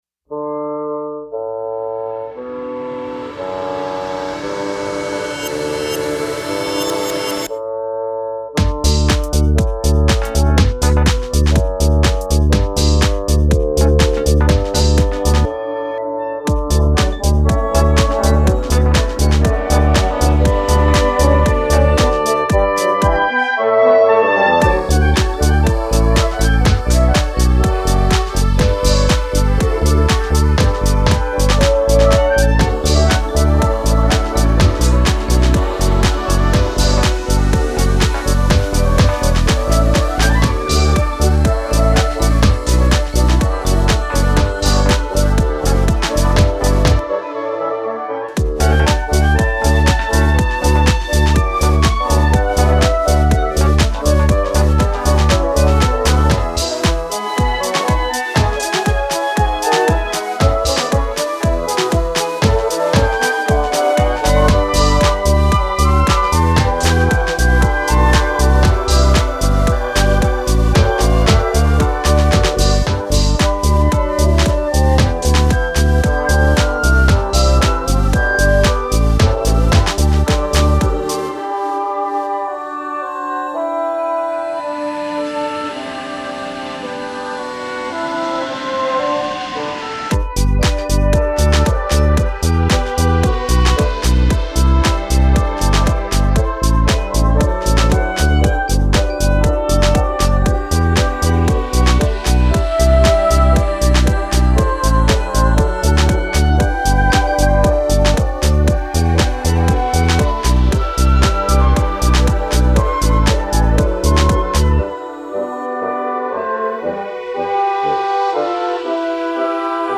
今プロデュースをさせて頂いているアンサンブルユニット
これをテクノ調にしてみた結果がこのザマです。。。泣あせる
○ドンカマ入れてないのに演奏のテンポが安定しないのは当然なのに
○せっかくのアンサンブルを完全に壊してしまった。。。
した結果。。。最後までグルーヴが崩壊していることに気づかなかった。。
○まったく録音の音と打ち込みの音が馴染まない。。なんで？？